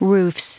Prononciation du mot roofs en anglais (fichier audio)
Prononciation du mot : roofs
roofs.wav